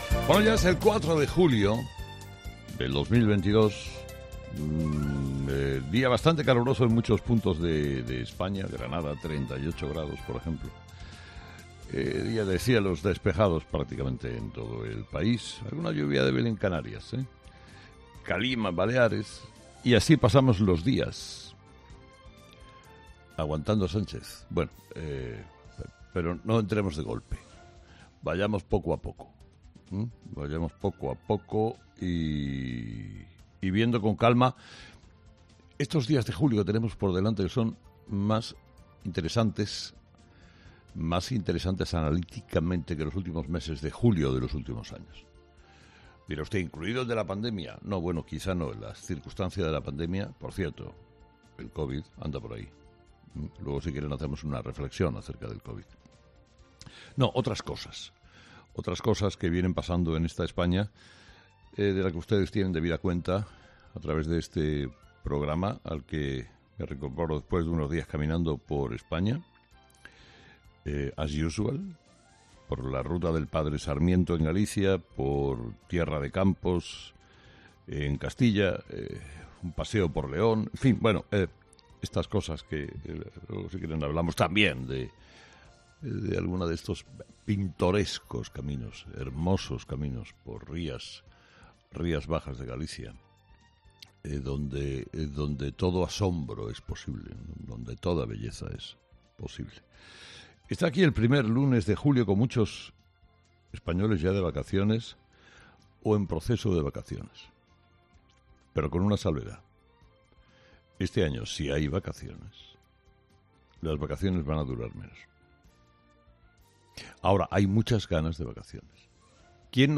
Carlos Herrera, director y presentador de 'Herrera en COPE', ha comenzado el programa de este miércoles analizando las principales claves de la jornada, que pasan, entre otros asuntos, por los principales retos a nivel político y económico, la semana después de la celebración de la Cumbre de la OTAN en Madrid.